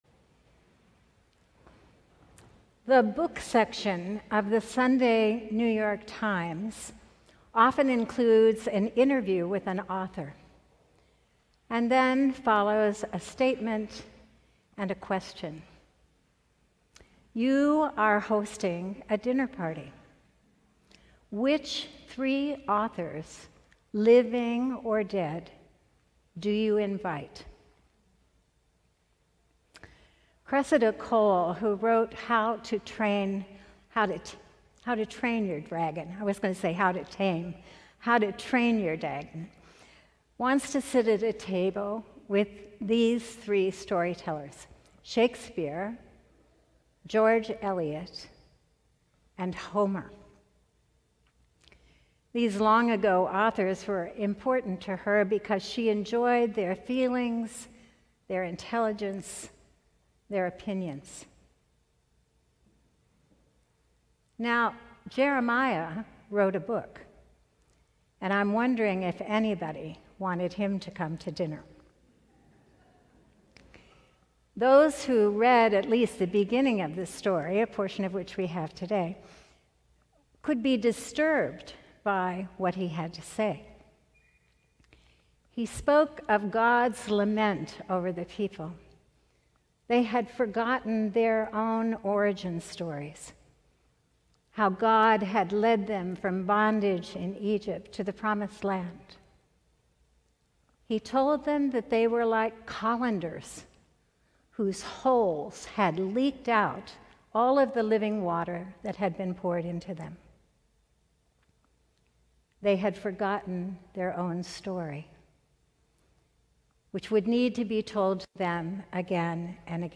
Sermon: Dinner Guests - St. John's Cathedral